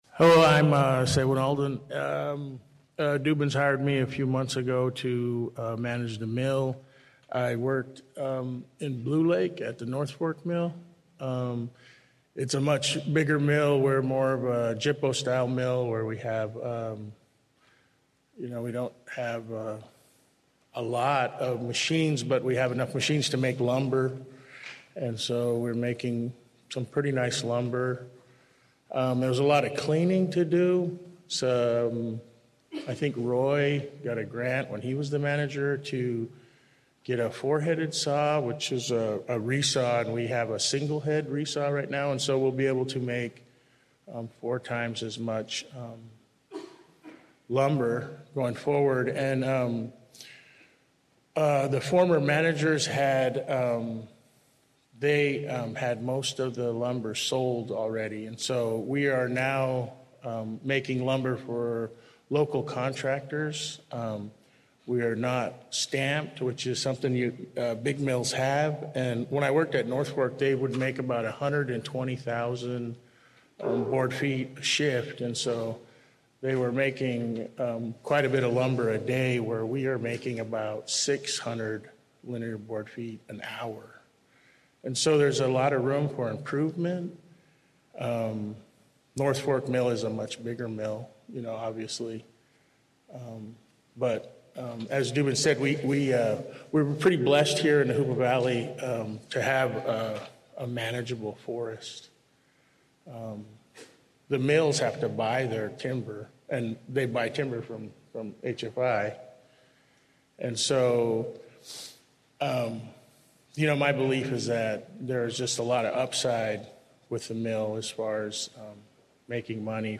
Continuing on with department reports we hear from Fisheries, PUD and K’ima:w Medical Center.
This audio resides in the Downloads category Local News